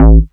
Sf Lo Bass.wav